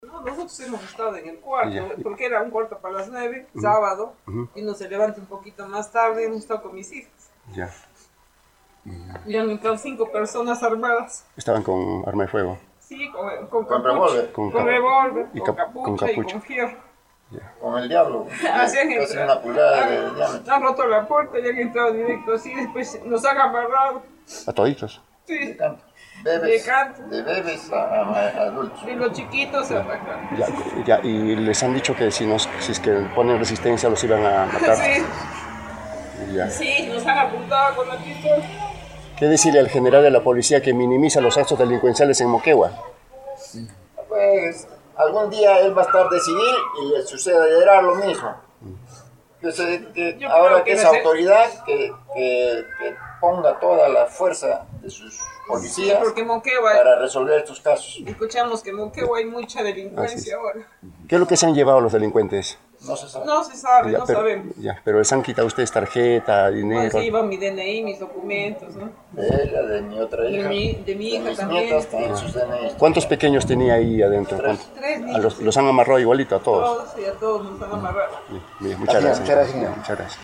A Radio Uno, relataron muy nerviosos los minutos de terror vividos e indicaron que estos facinerosos rebuscaron por toda la casa y finalmente se llevaron documentos personales, pero no era conocido el valor de las pérdidas materiales dado que el peritaje demoraba.